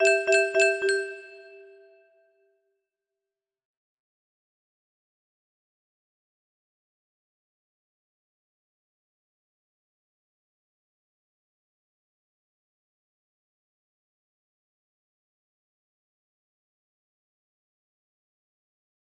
3C99 midi exe music box melody